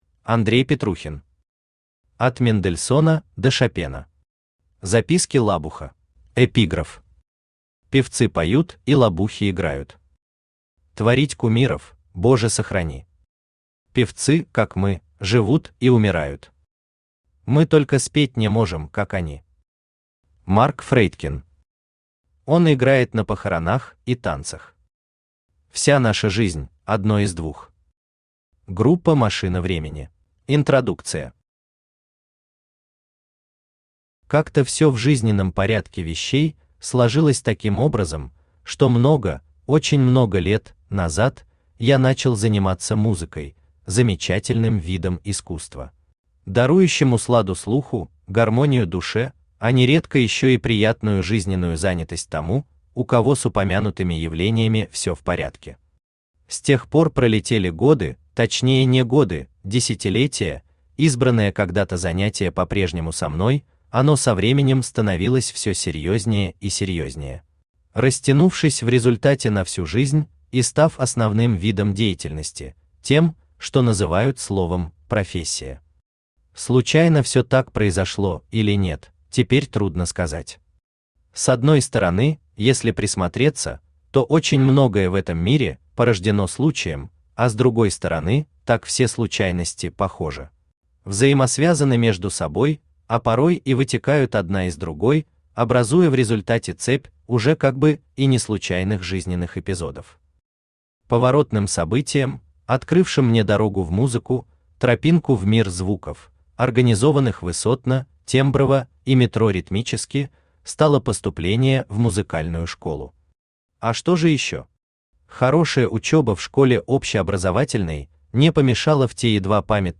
Аудиокнига От Мендельсона до Шопена. Записки лабуха | Библиотека аудиокниг
Записки лабуха Автор Андрей Петрухин Читает аудиокнигу Авточтец ЛитРес.